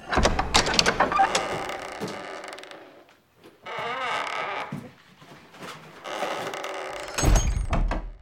church_1.ogg